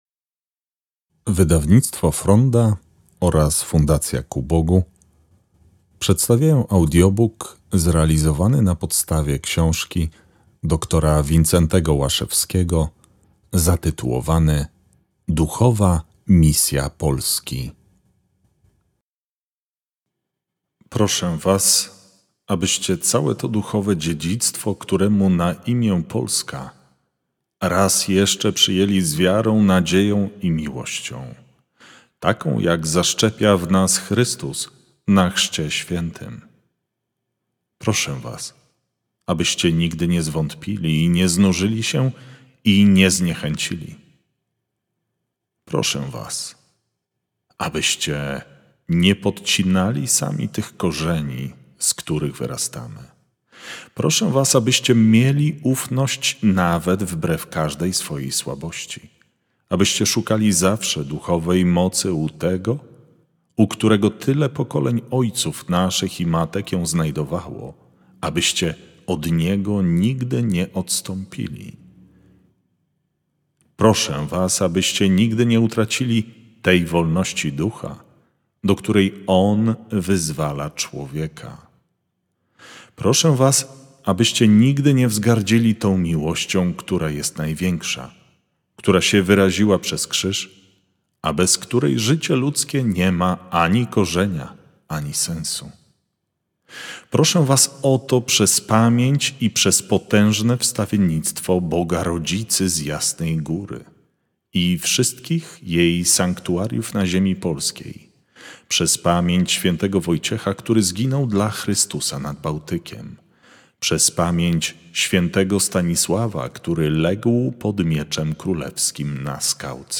Duchowa misja Polski – Audiobook